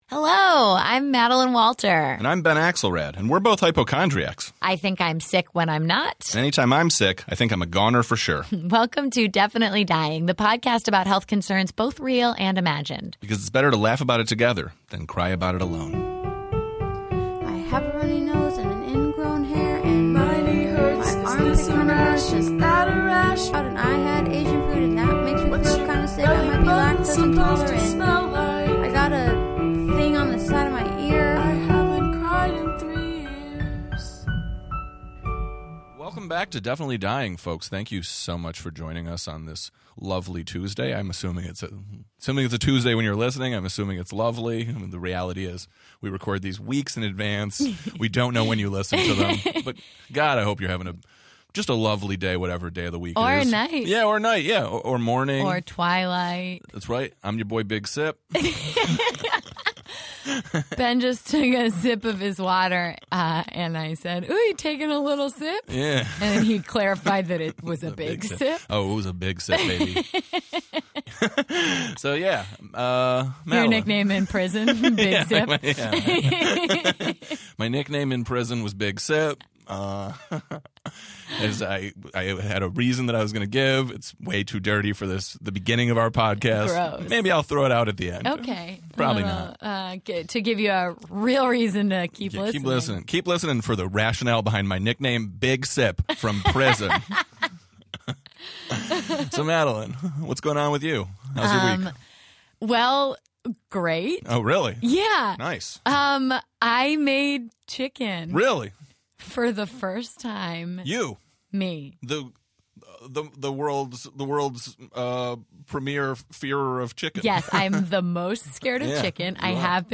This week D’Arcy Carden (The Good Place, Board City) swings by to talk about Charles Manson, her fear of being murdered, high school accidents, whether or not there’s an afterlife, and more! After the interview, D’Arcy sticks around for a fun game of After Live/After Death!